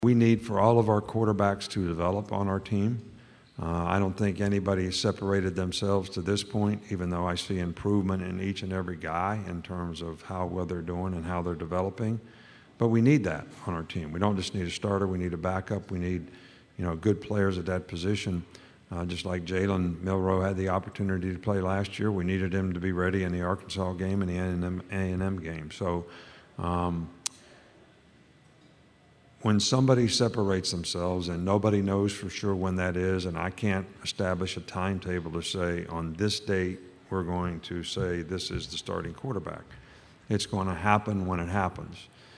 During his time on the podium, Saban talked quarterback disputes, player development, staff changes and additions and more on what’s to come this season.